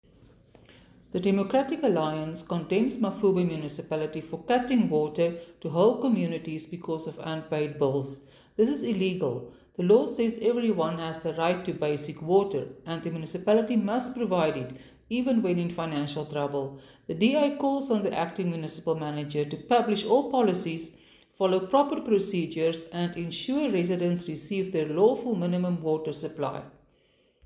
Issued by Cllr. Suzette Steyn – DA Councillor Mafube Municipality